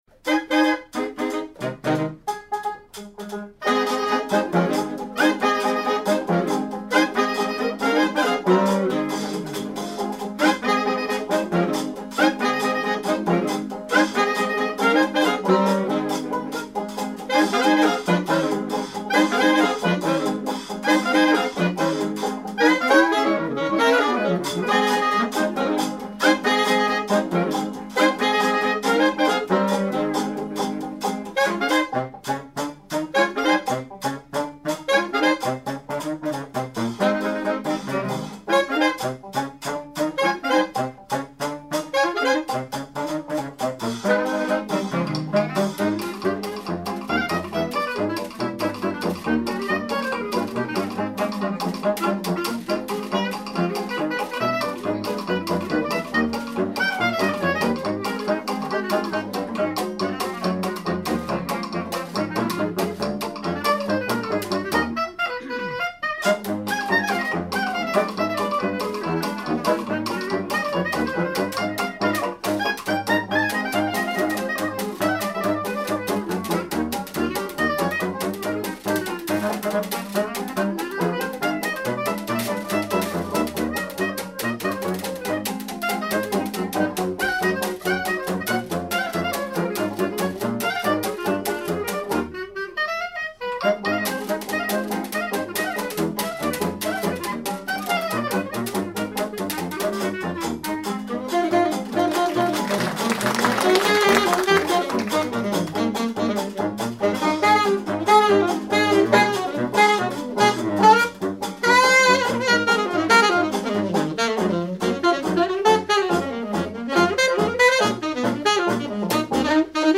clarinette
saxophone alto
saxophone baryton
banjo
batterie, washboard